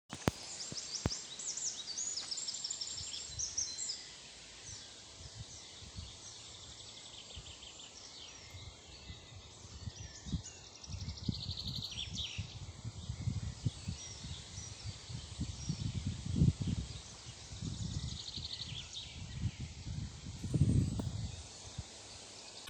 Wren, Troglodytes troglodytes
Ziņotāja saglabāts vietas nosaukumsAlūksnes nov. Opekalns
StatusSinging male in breeding season